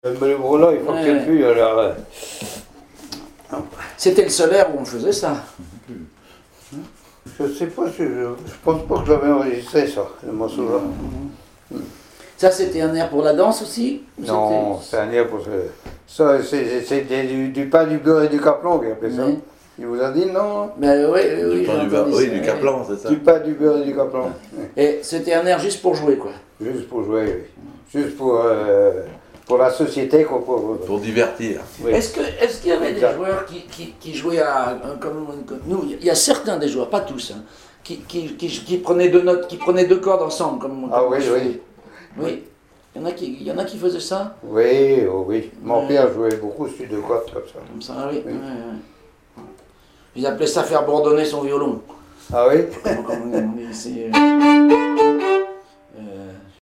Catégorie Témoignage